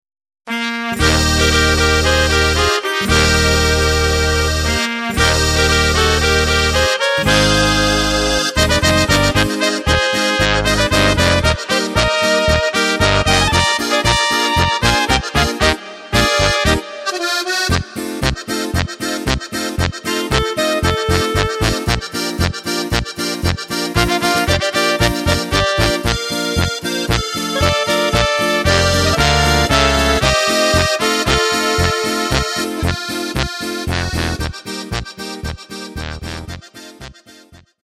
Takt: 2/4 Tempo: 115.00 Tonart: Eb
Beschwingte Polka